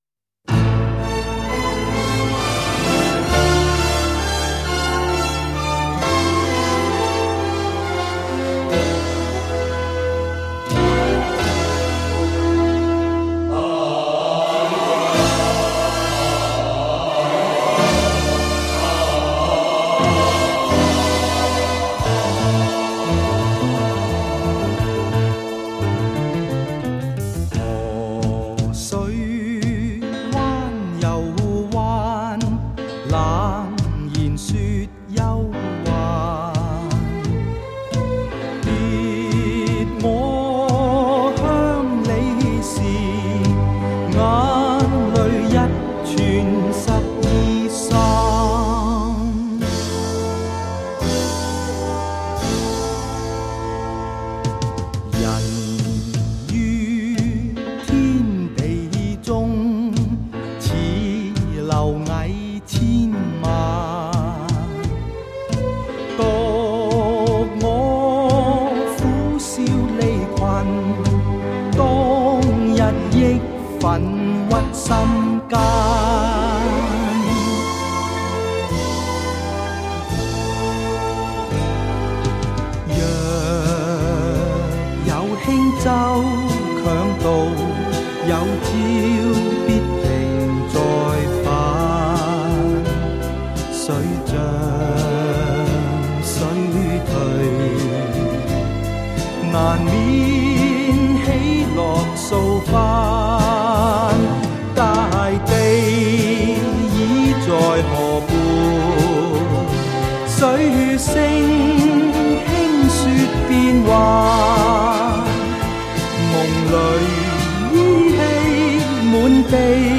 香港DSD版
用DSD技术重新编码压制